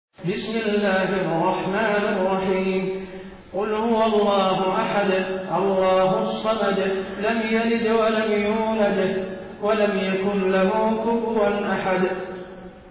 taraweeh-1433-madina